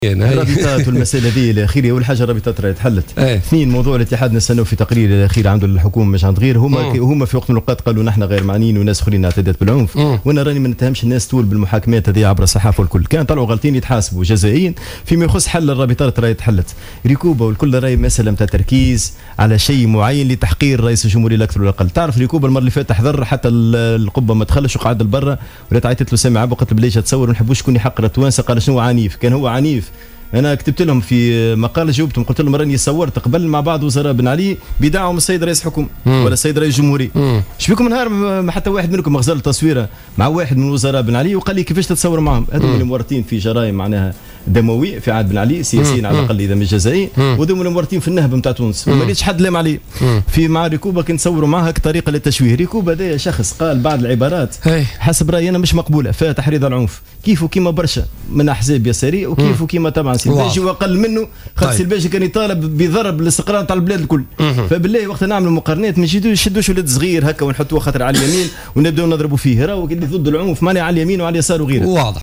علّق محمد عبو ضيف برنامج "بوليتيكا" اليوم الثلاثاء على الصورة التي جمعت زوجته سامية عبو بريكوبا.